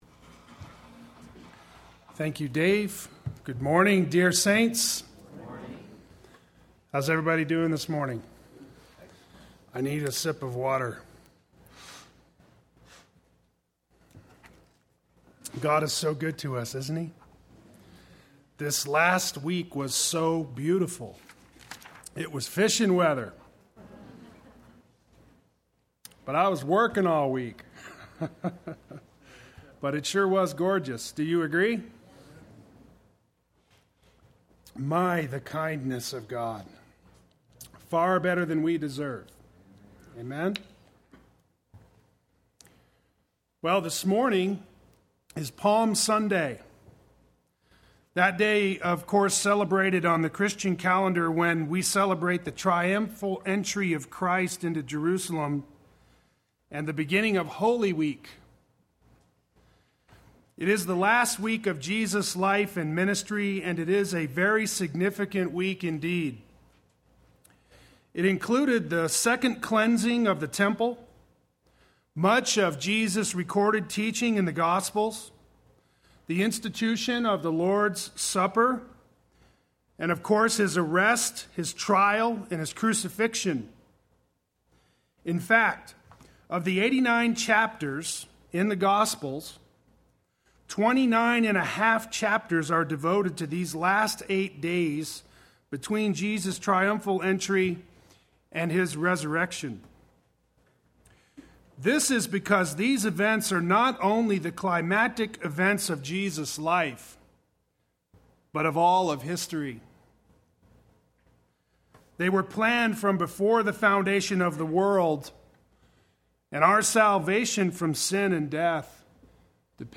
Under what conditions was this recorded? The King Has Arrived Sunday Worship